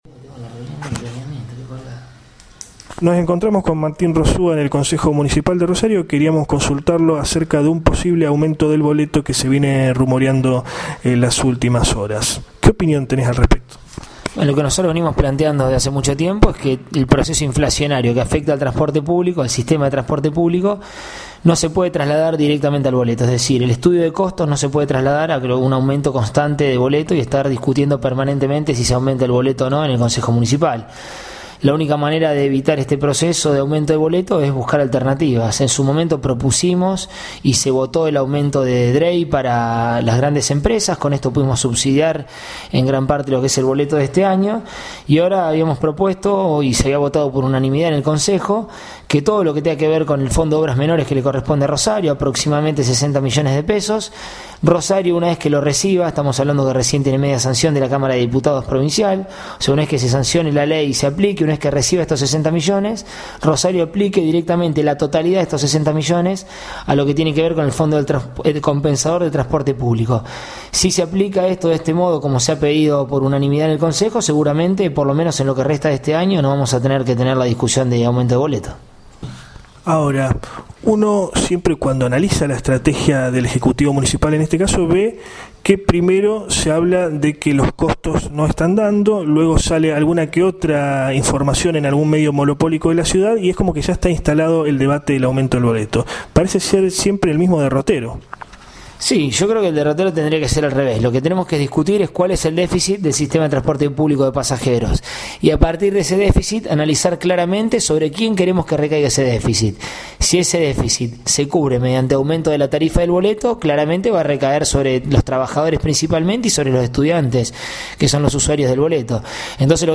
MARTÍN ROSÚA AUDIO ENTREVISTA
Cuna de la Noticia entrevistó al Concejal Martín Rosúa, presidente del Bloque «UCR 1983» en el Concejo Municipal de Rosario, y referente del Movimiento de Acción Radical (MAR).